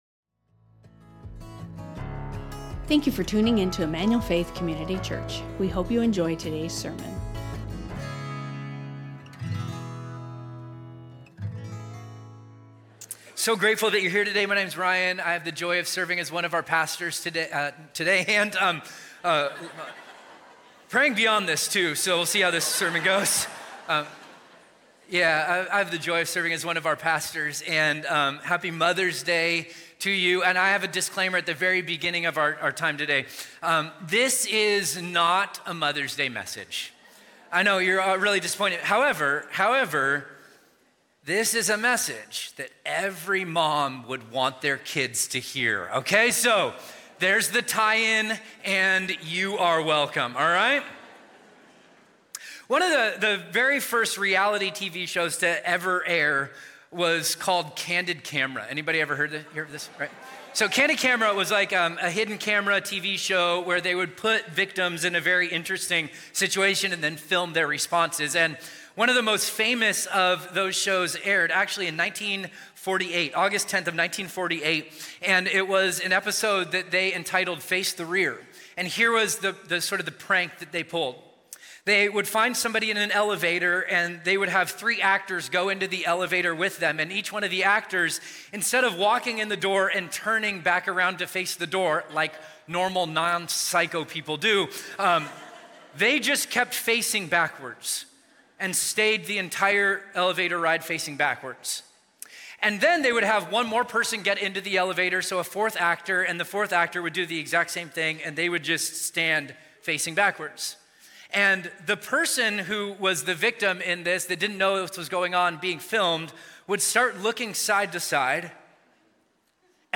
Emmanuel Faith Sermon Podcast